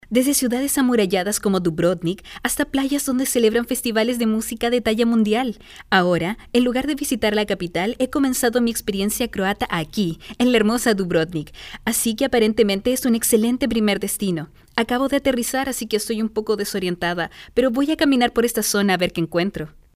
Latin American female voice overs